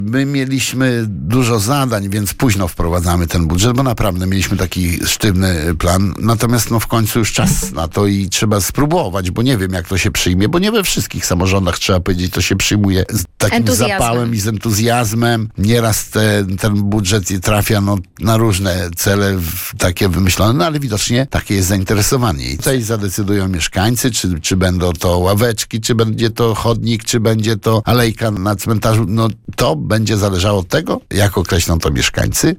Burmistrz Andrzej Duda mówił na naszej antenie, że, podobnie jak w innych samorządach, to tylko i wyłącznie mieszkańcy w głosowaniu wybiorą propozycje, na które chcą przeznaczyć pieniądze z budżetu.